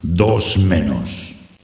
flak_m/sounds/announcer/est/doublekill.ogg at 9e43bf8b8b72e4d1bdb10b178f911b1f5fce2398
doublekill.ogg